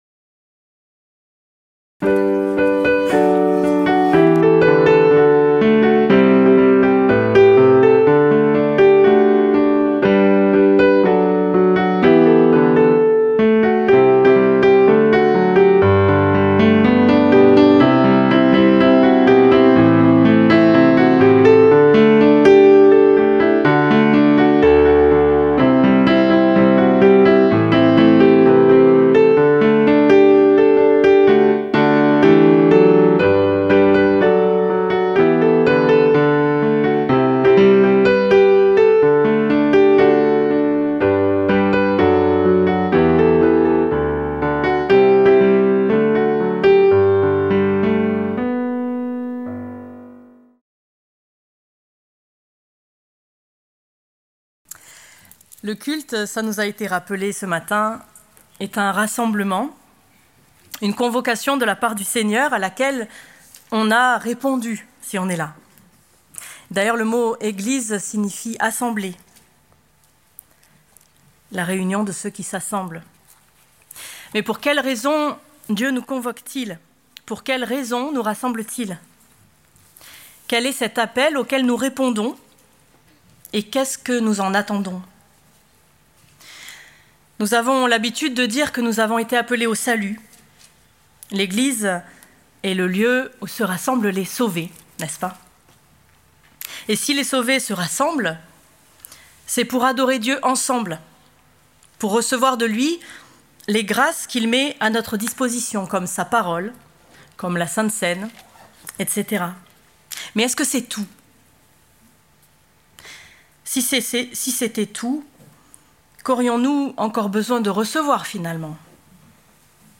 Prédication du 08 Décembre 2024.